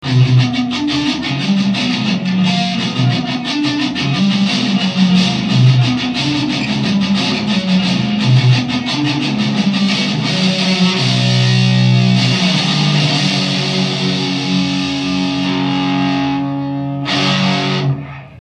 Hangminták: